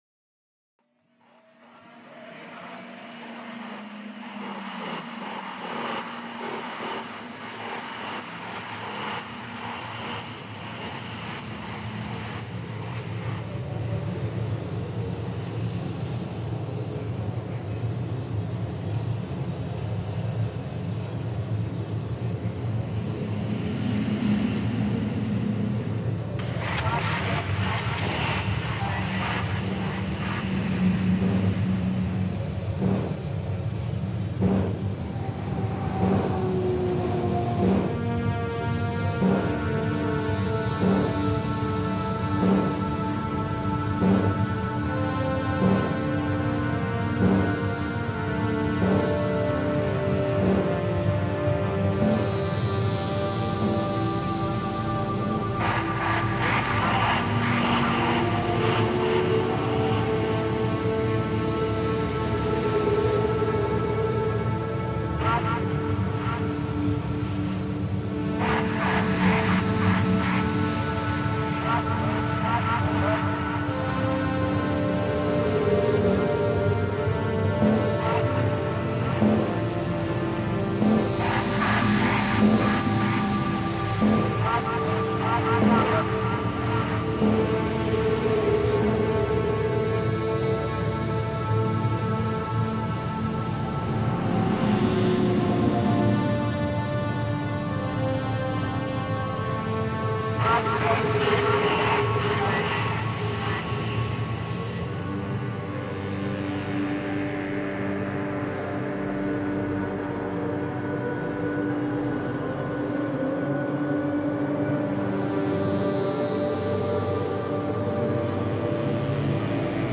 Vietnam steelworking reimagined